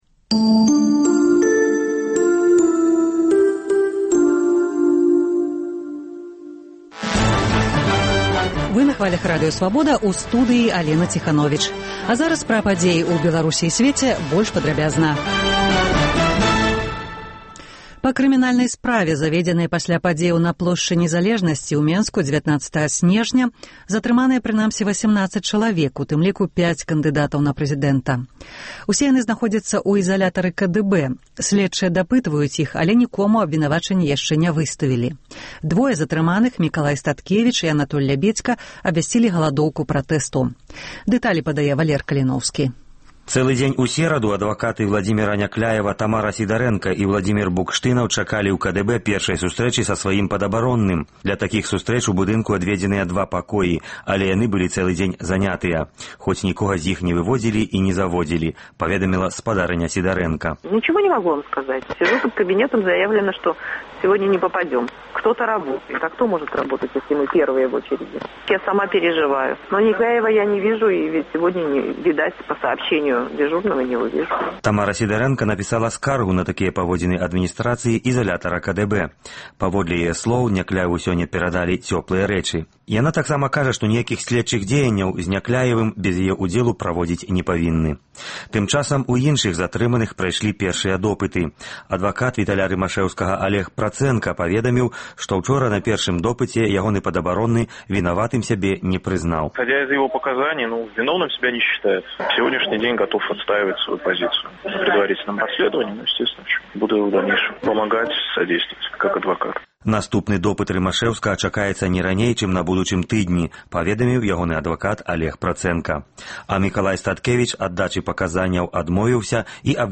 Інфармацыйны блёк: навіны Беларусі і сьвету Паведамленьні нашых карэспандэнтаў, званкі слухачоў, апытаньні ў гарадах і мястэчках Беларусі.